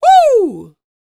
D-YELL 1201.wav